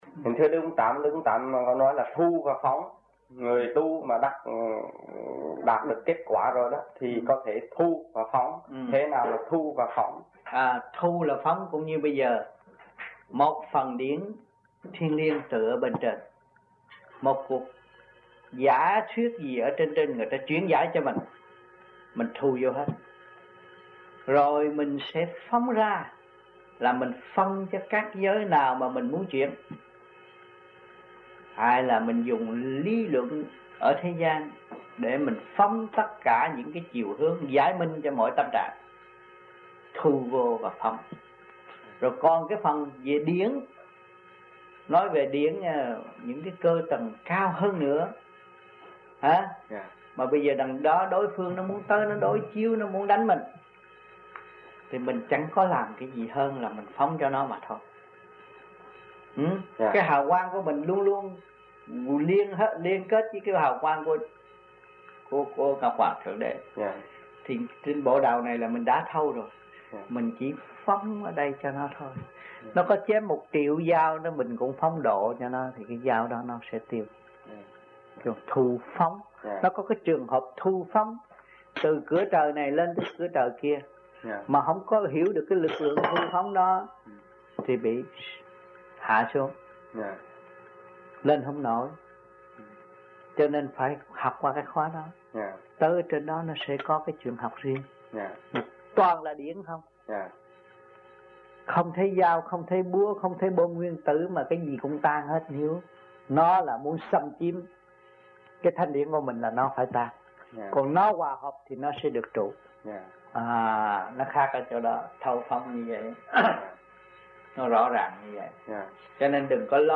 1980-01-19 - MONTREAL - THUYẾT PHÁP 3